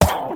Sound / Minecraft / mob / witch / hurt3.ogg
hurt3.ogg